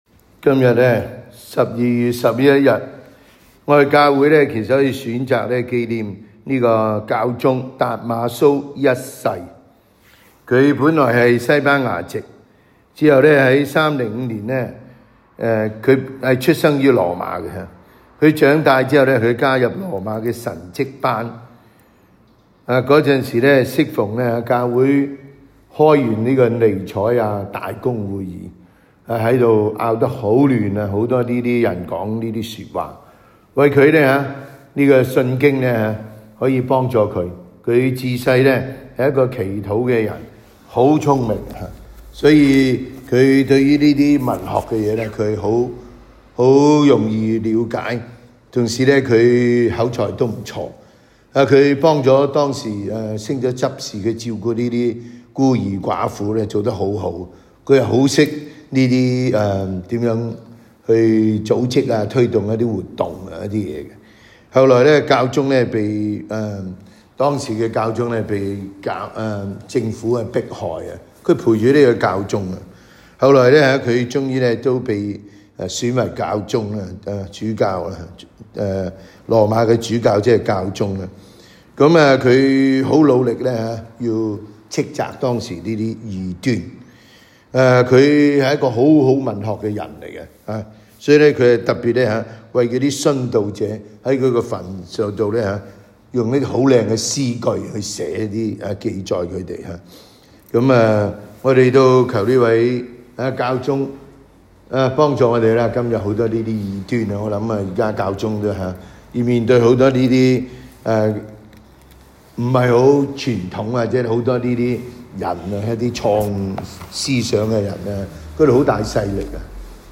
SDB 每日講道及靈修講座